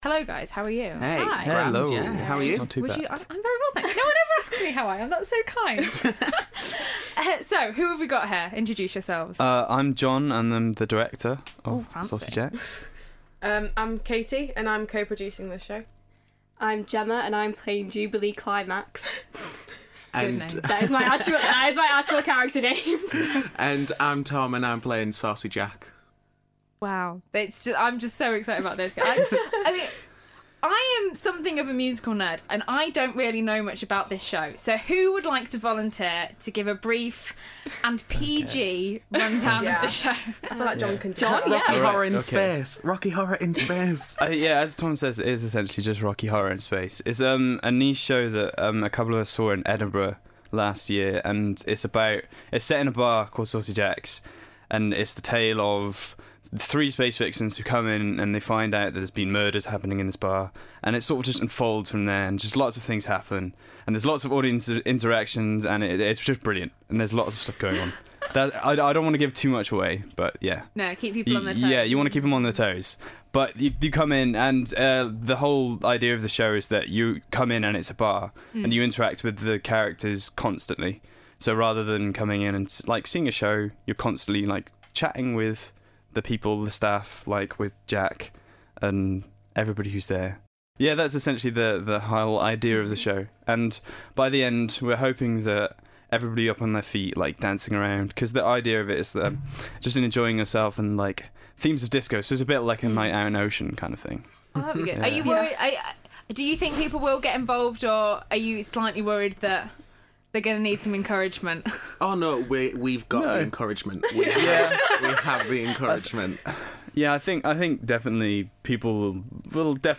Showtime: Musicality Interview